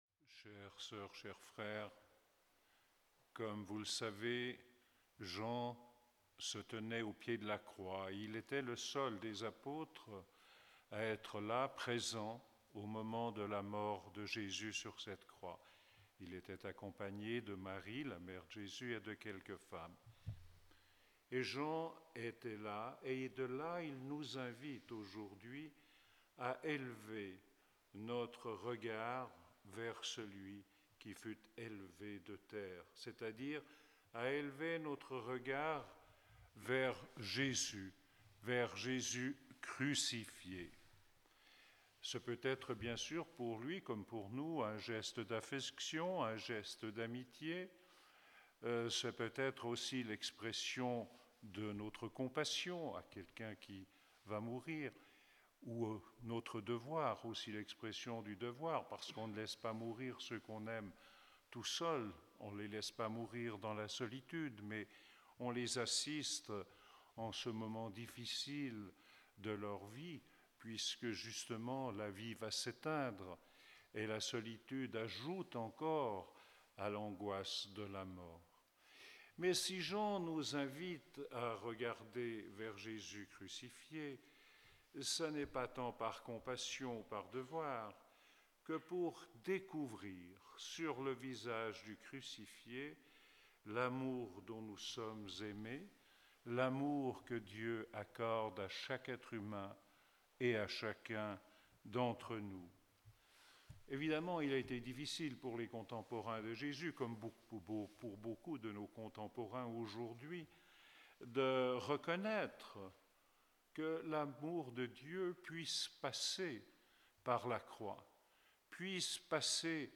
Enregistrement : l'homélie
Pour ce dimanche « Lætare ! » de la quatrième semaine de Carême